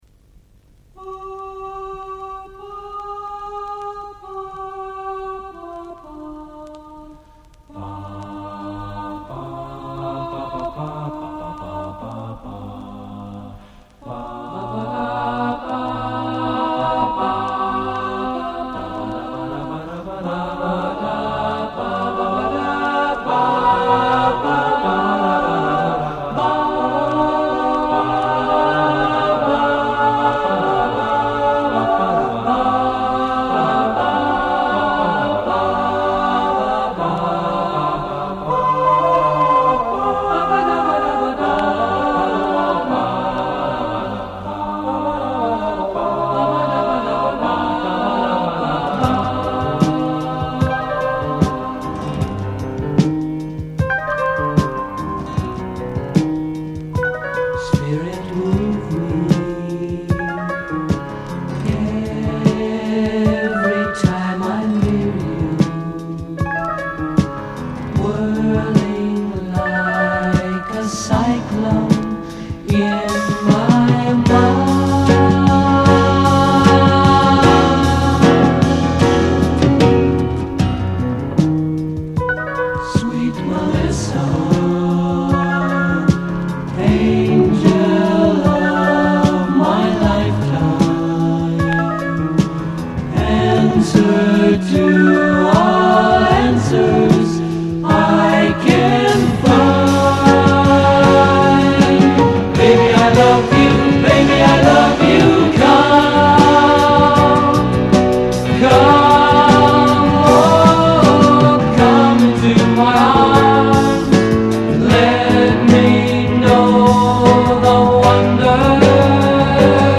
I like the cosmick twinkle-twinkle-twinkling